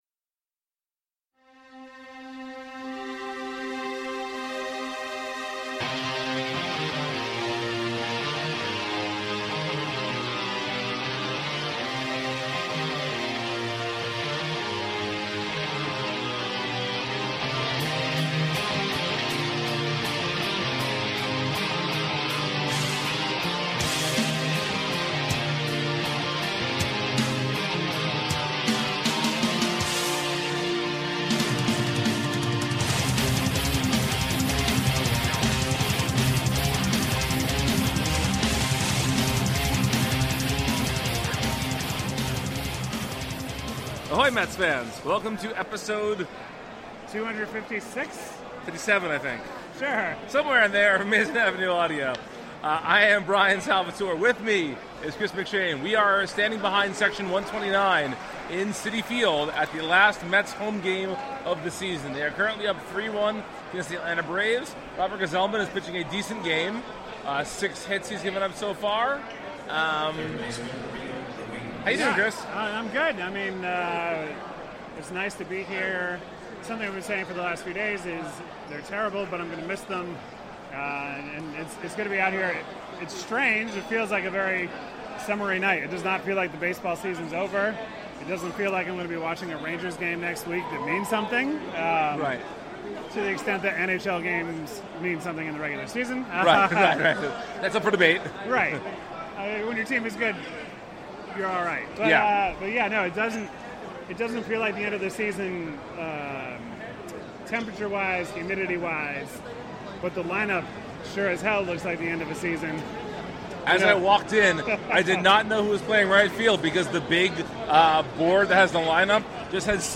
are joined by a slew of Mets fans at the last home game of the year.